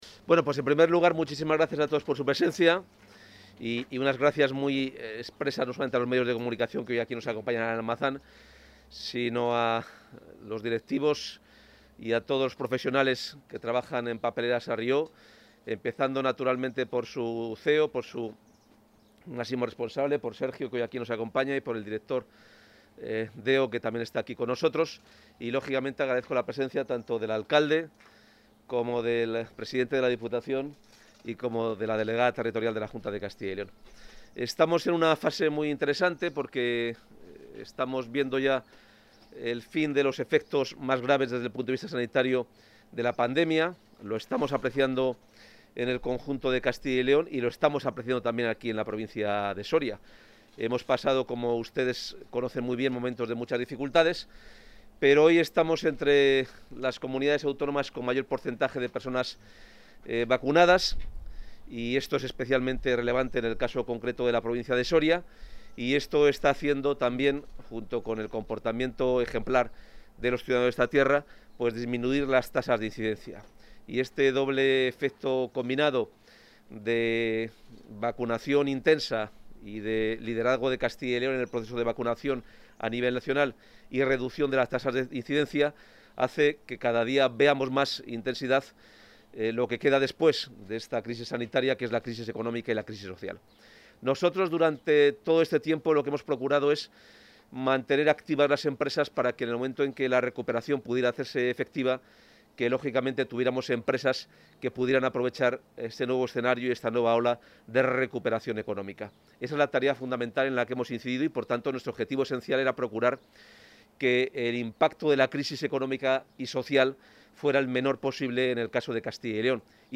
El consejero de Economía y Hacienda, Carlos Fernández Carriedo, ha visitado las instalaciones de Sarrió Papelera de...
Intervención del consejero de Economía y Hacienda.